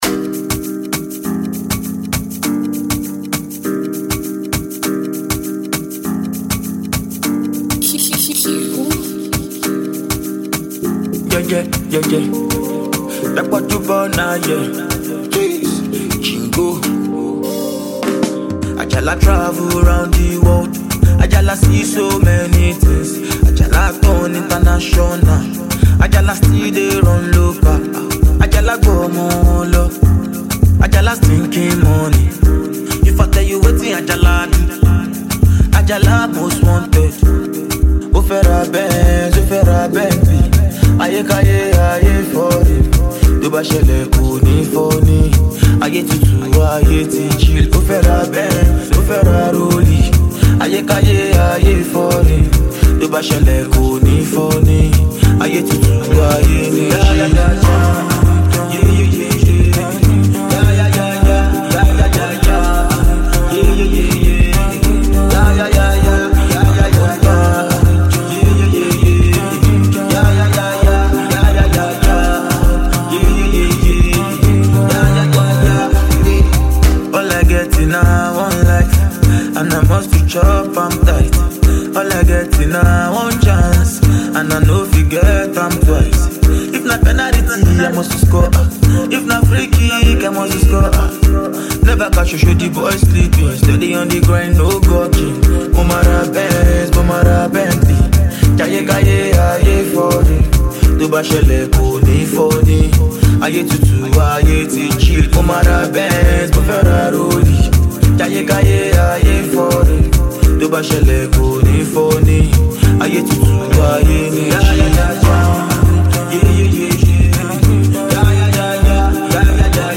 and afrobeat rhythms
melodious and memorable sounds